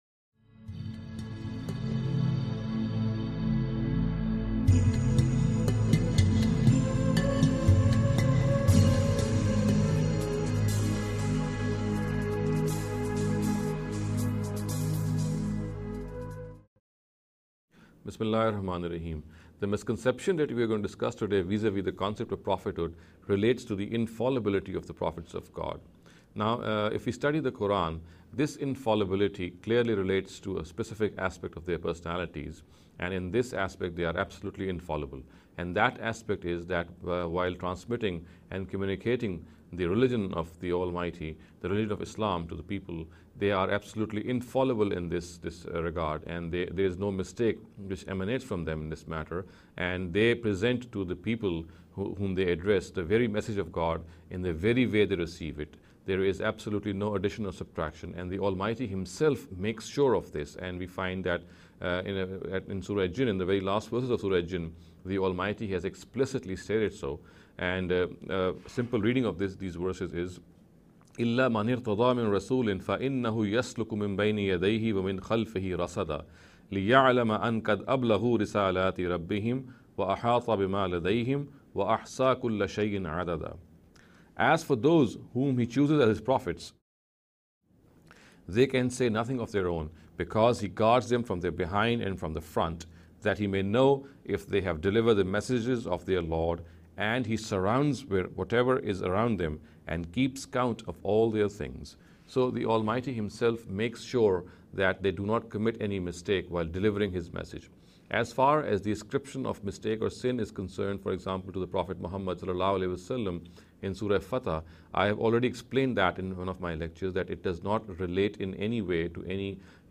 This lecture series will deal with some misconception regarding the Concept of Prophethood.